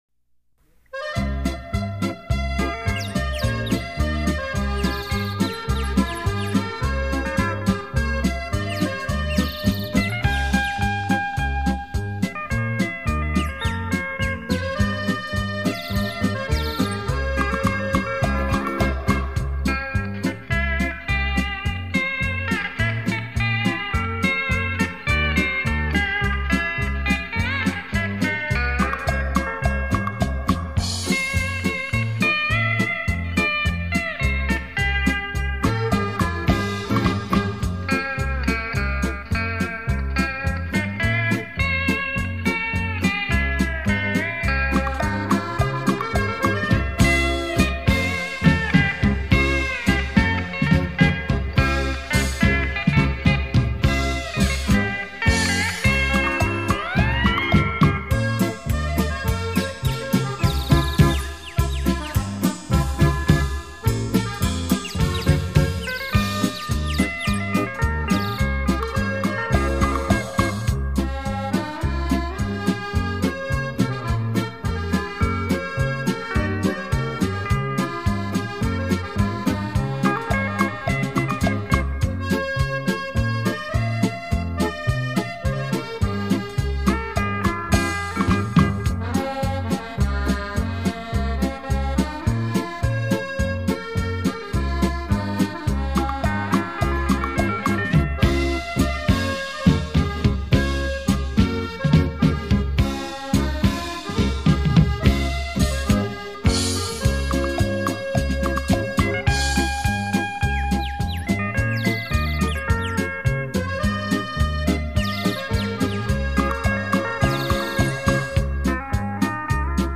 专辑类型：电子琴音乐
曲曲精選 曲曲動聽，电子琴音域较宽，和声丰富，
发音音量可以自由调节，甚至可以演奏出一个管弦乐队的效果，
另外，电子琴还安装有混响回声，延长音、震音和颤音等
精心打造完美电子音乐，立體效果 環繞身歷聲 超魅力出擊，